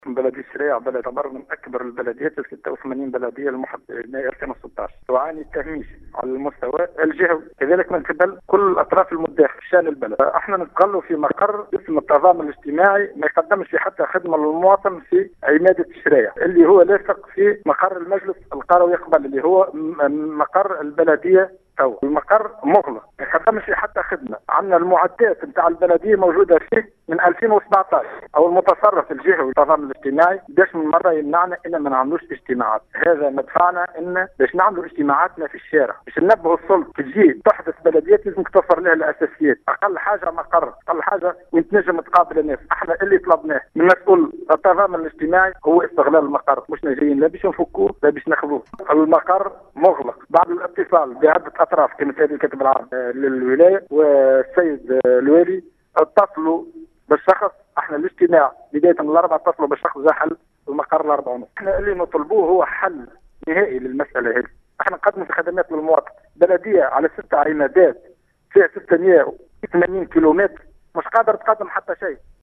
في اتصال باذاعة السليوم ، اكد رئيس بلدية الشرايع مشرق الشمس ناجم صالحي ان البلدية تعاني عديد الصعوبات المتمثل بالاساس في ايجاد مقر للبلدية يستجيب لاحتياجات الاعوان و المواطنين ، و هو ما اضطرهم الي عقد اجتماعاتهم بمقر التضامن الاجتماعي المحاذى لمقر البلدية .